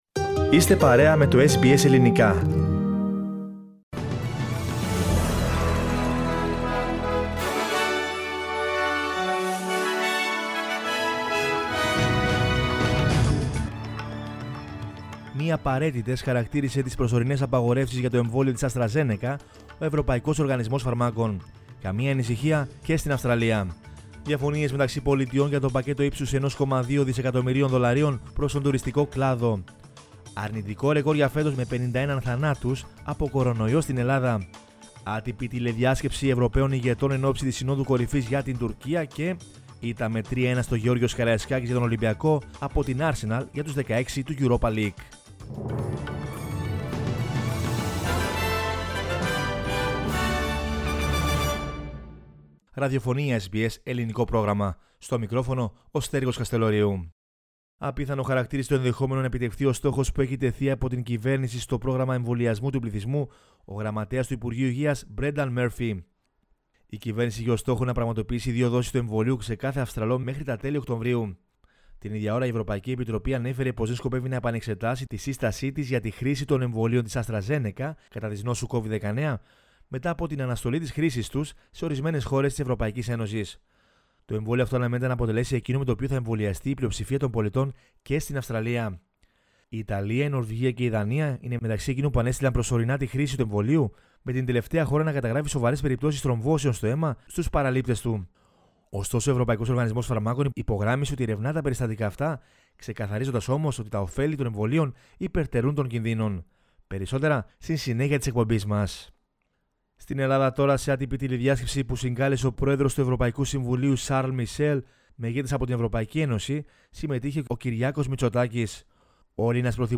News in Greek from Australia, Greece, Cyprus and the world is the news bulletin of Friday 12 March 2021.